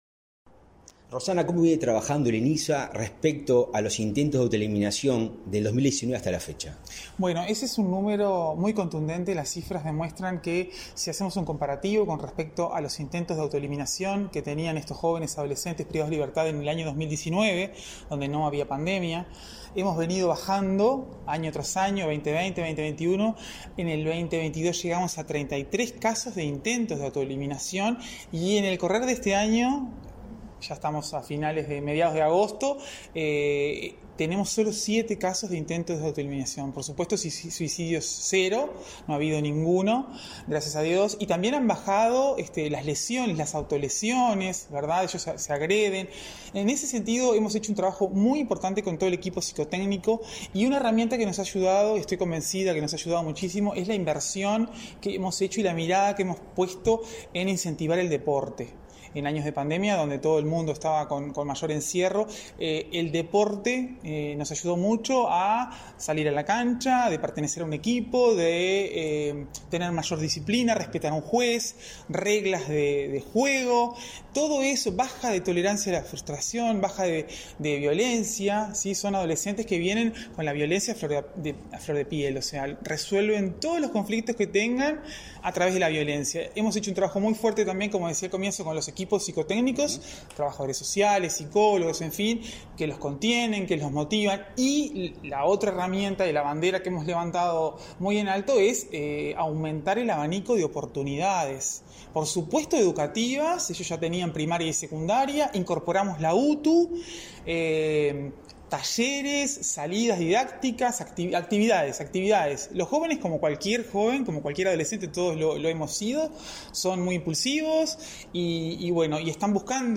Entrevista a la presidenta del INISA, Rossana de Olivera
La presidenta del Instituto Nacional de Inclusión Social Adolescente (INISA), Rossana de Olivera, en declaraciones a Comunicación Presidencial,